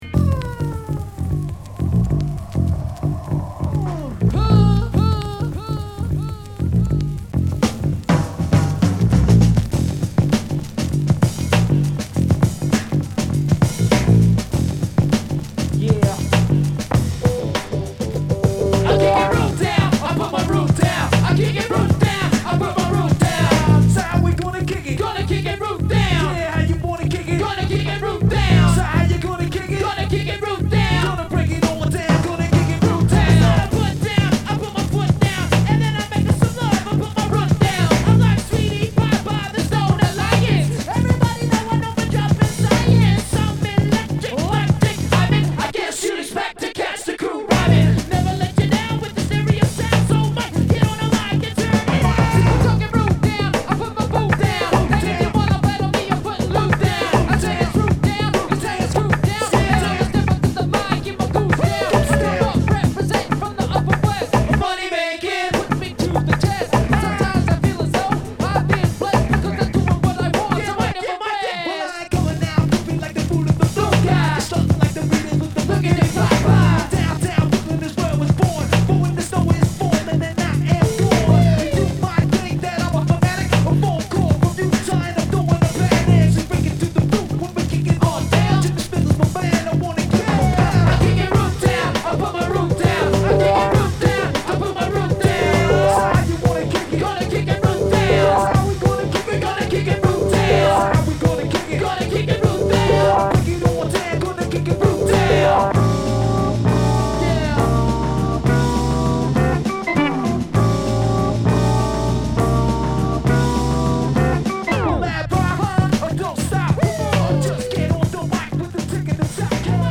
＊スリキズ有り。